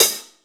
Index of /90_sSampleCDs/Total_Drum&Bass/Drums/HiHats
5a_closed.wav